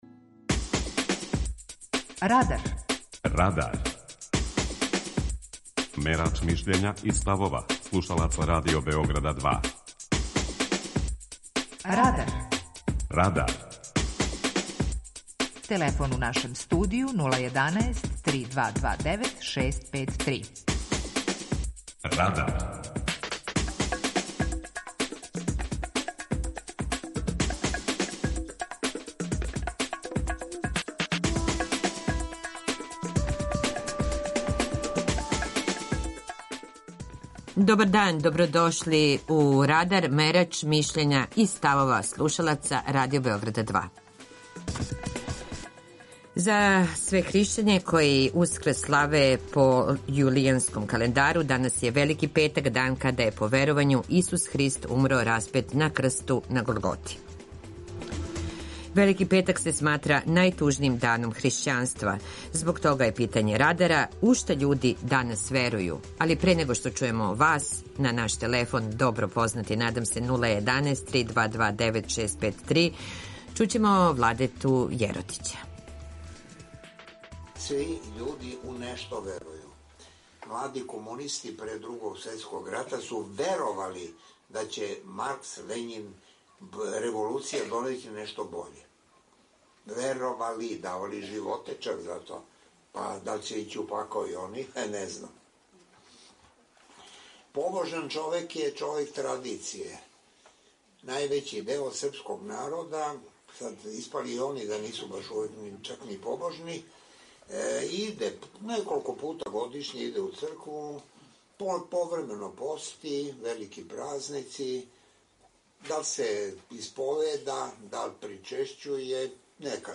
Питање Радара је: У шта људи данас верују? преузми : 18.34 MB Радар Autor: Група аутора У емисији „Радар", гости и слушаоци разговарају о актуелним темама из друштвеног и културног живота.